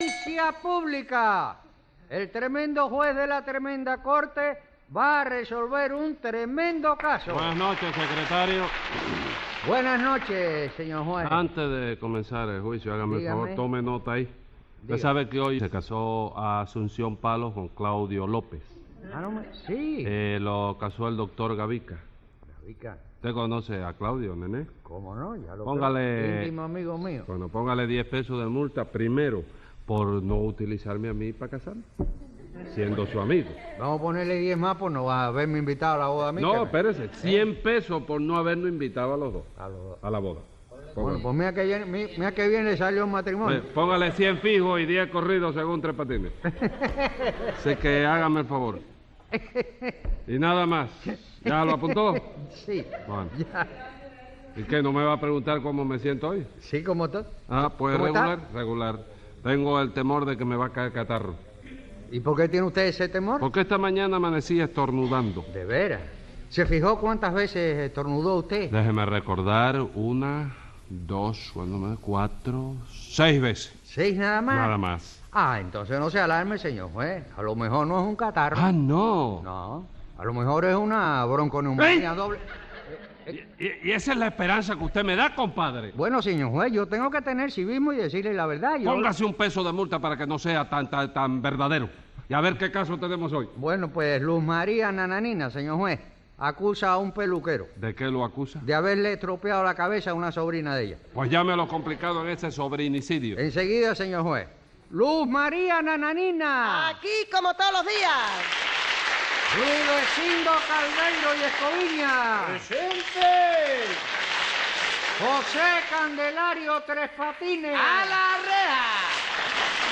Divertidísimas situaciones en el más puro humor cubano en el legendario show de Tres Patines.